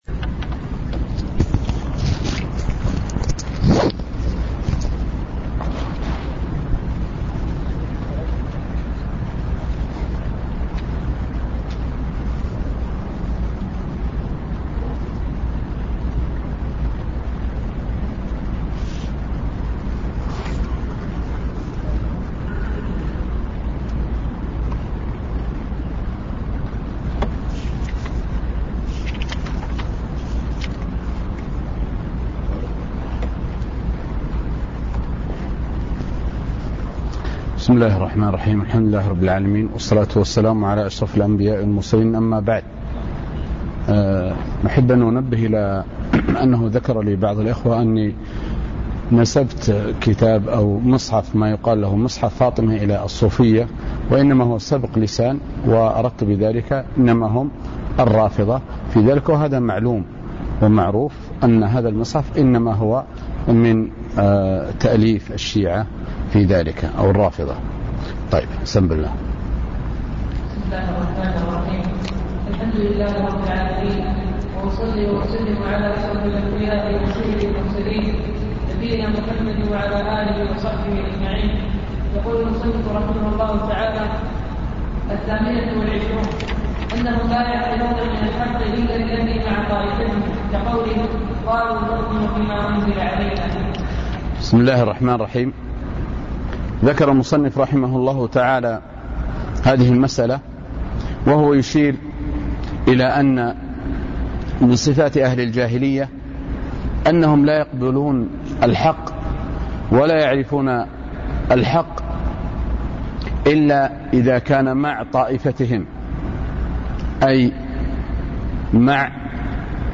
ضمن الفصل الأول للدورة العلمية المكثفة الثانية بجامع القاضي بعنيزة